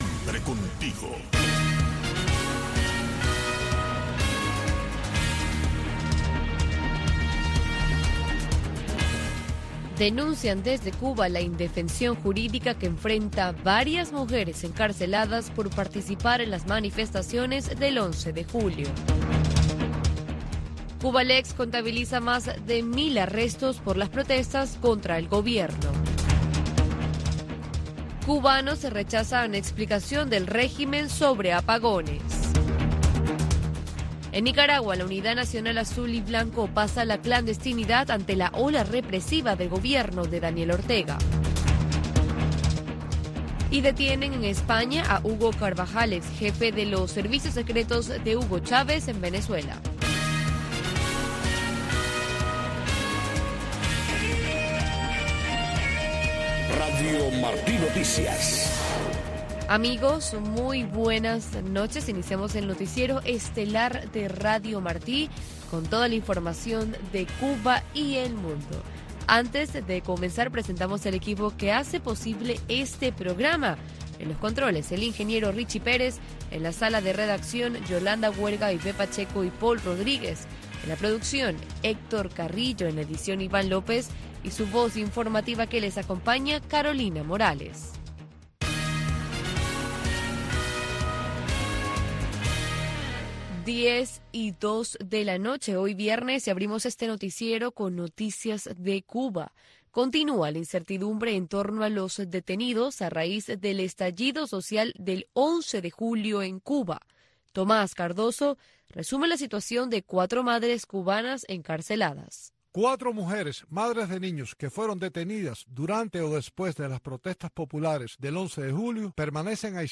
Noticiero de Radio Martí 10:00 PM